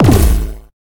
laser.ogg